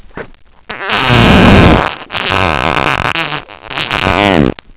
fart14.wav